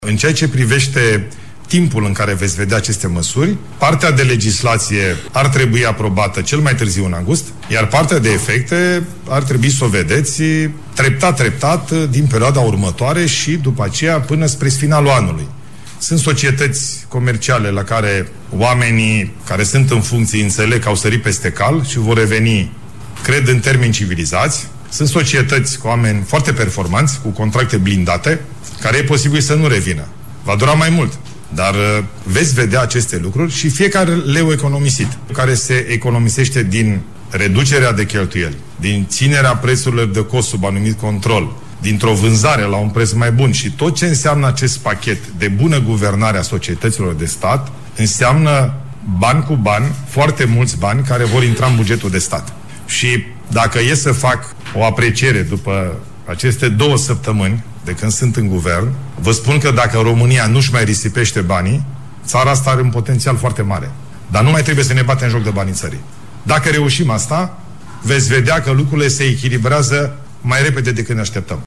Într-o conferinţă de presă susţinută în această după-masă la Palatul Victoria, şeful executivului a mai anunţat că vrea administraţii locale mai eficiente, cu un personal care să ţină cont de numărul de locuitori, dar şi pensionarea magistraţilor la vârsta standard.
În legătură cu orizontul de timp necesar aplicării acestor măsuri, premierul Bolojan a declarat: